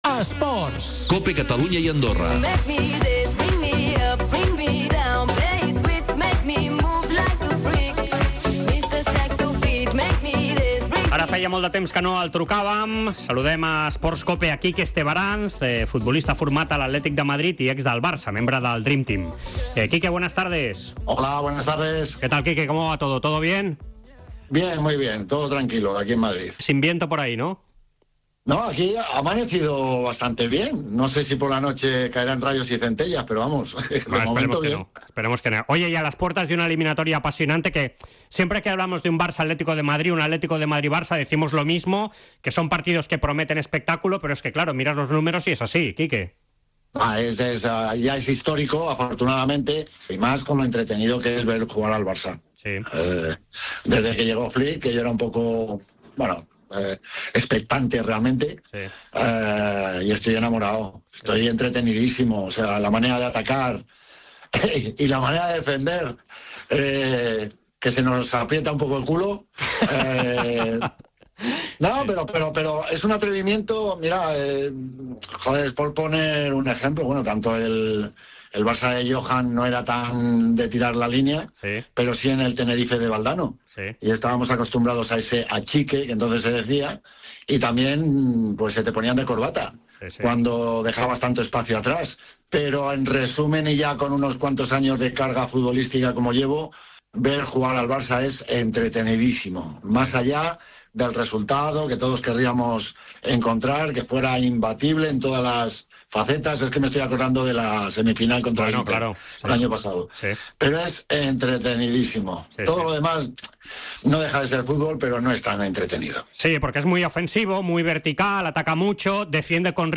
Entrevista al ex jugador del Barça y Atleti de Madrid.